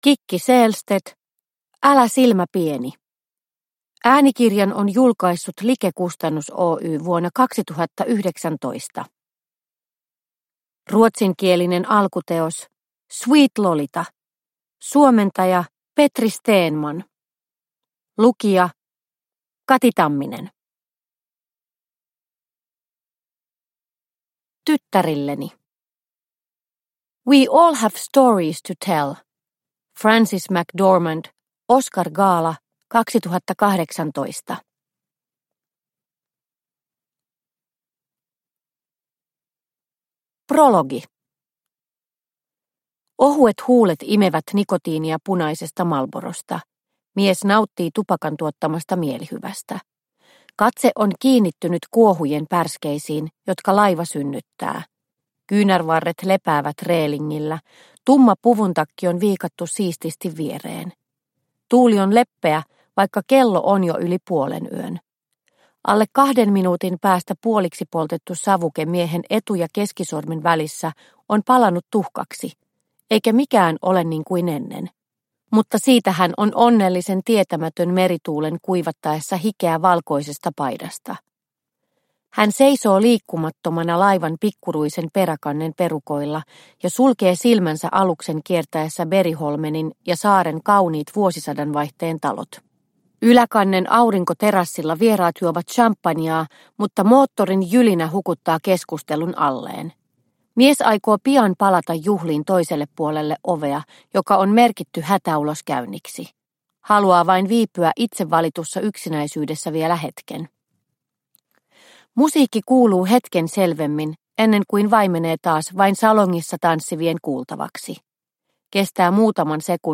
Älä silmä pieni – Ljudbok – Laddas ner